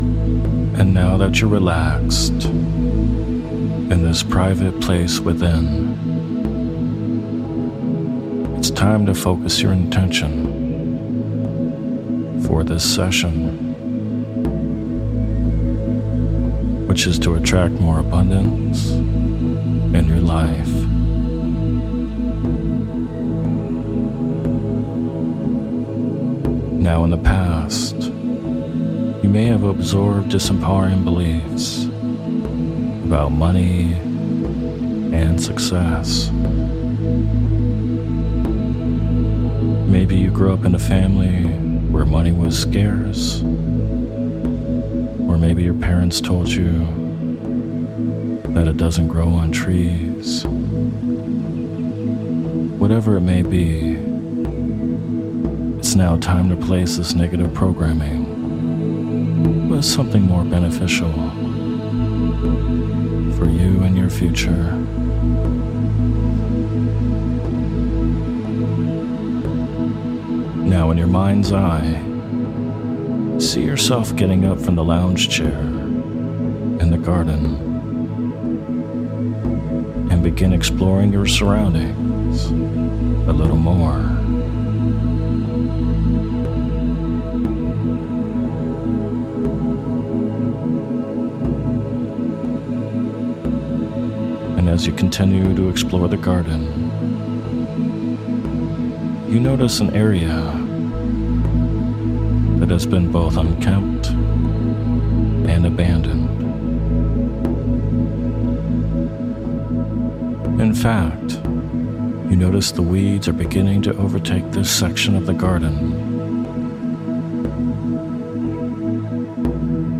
The Garden of Prosperity With Isochronic Tones
In this hypnosis session, we’ll use a garden metaphor to help release any limiting beliefs or money blocks you may have towards achieving abundance.